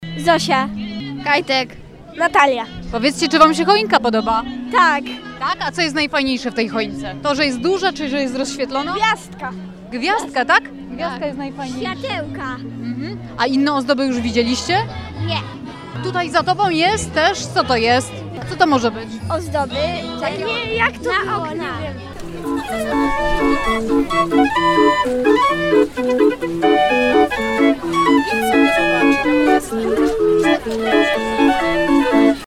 Bielsko-Biała: tłumy na Pl. Chrobrego w trakcie rozświetlenia choinki.